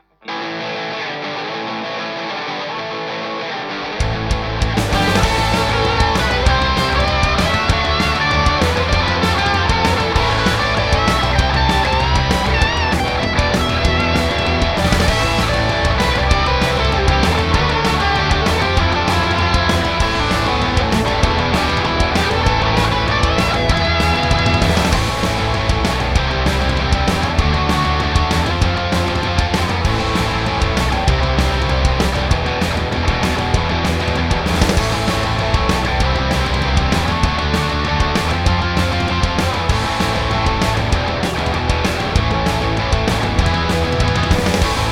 I've just plugged in and hacked this out so I'll re-track over the weekend but it should turn out OK.